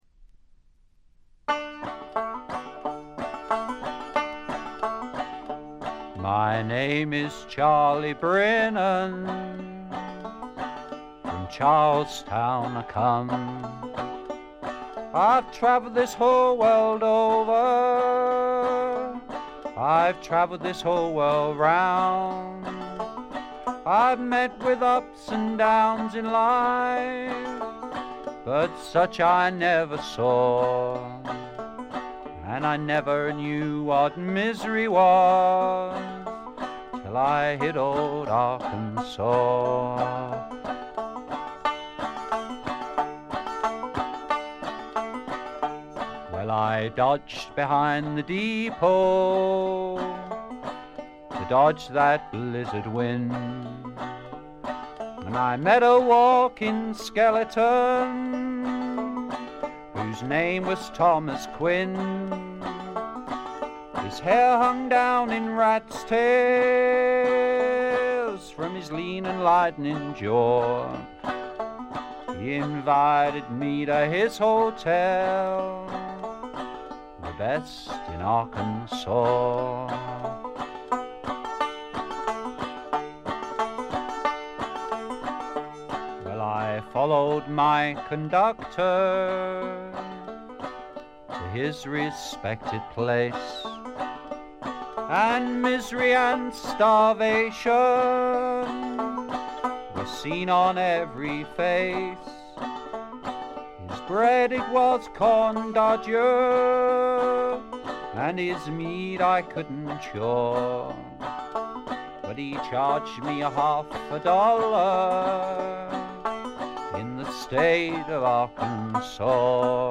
基本はバンジョーをバックにした弾き語りで、訥々とした語り口が染みる作品です。
試聴曲は現品からの取り込み音源です。